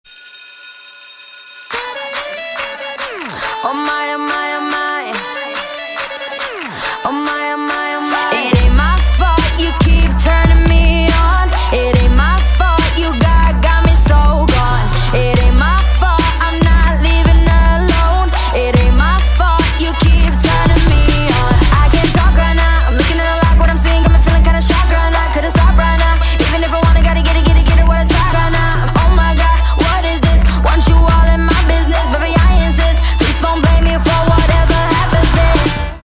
• Качество: 256, Stereo
Хип-хоп
dance
Trap
RnB
Bass
vocal